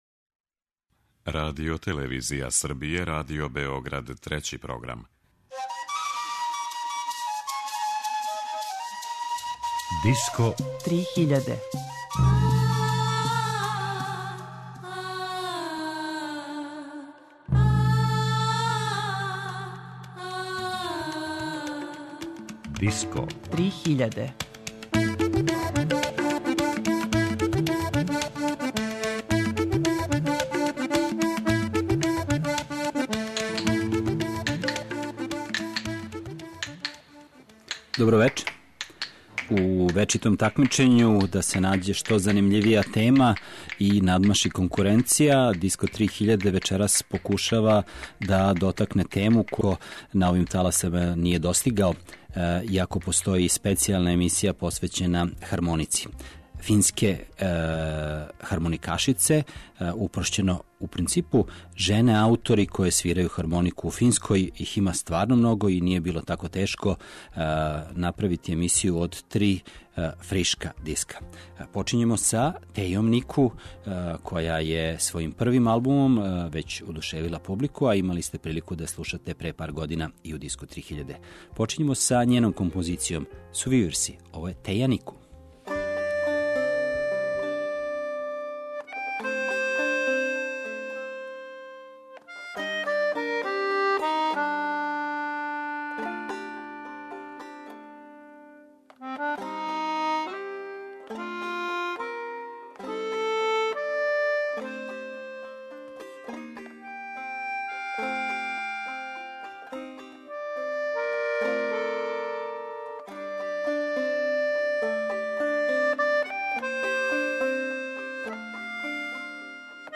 Жене које свирају хармонику.
У вечерашњој емисији, још једна наизглед необична тема, жене лидери које свирају хармонику, а све су Финкиње.